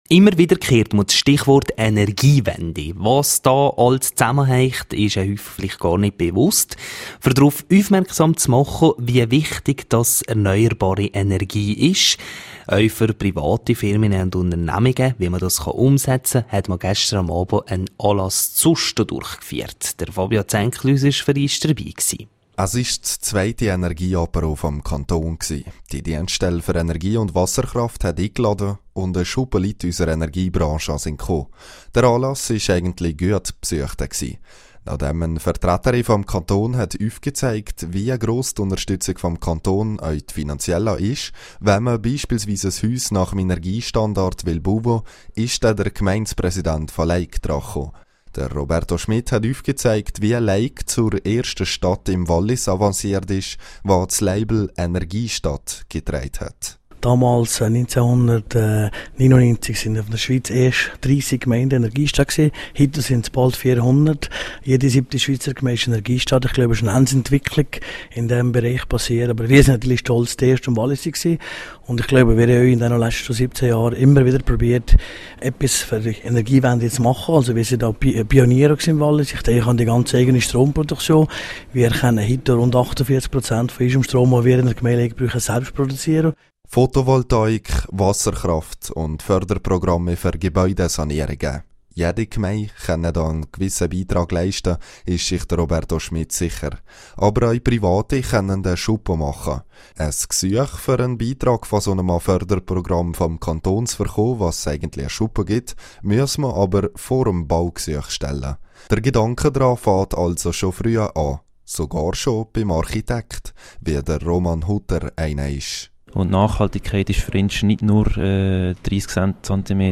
Der Anlass im Zentrum Sosta in Susten war gut besucht.